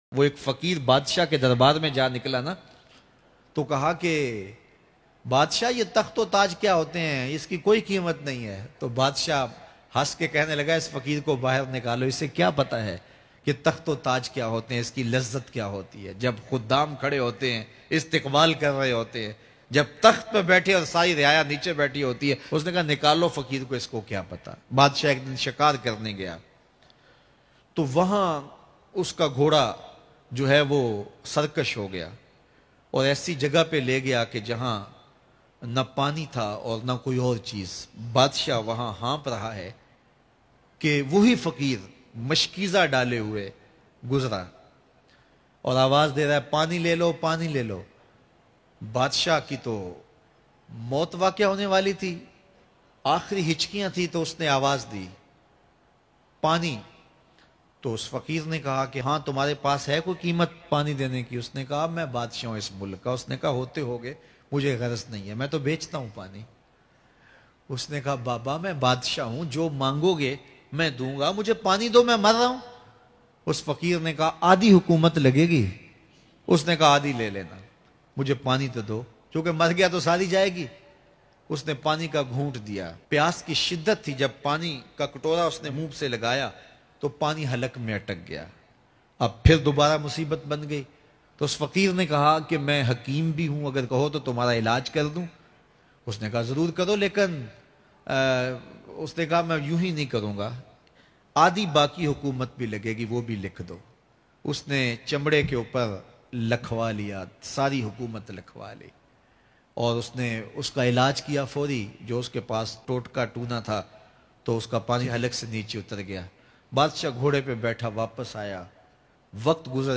2 Ghoont Pani K Badle Badshaahat Bayan Mp3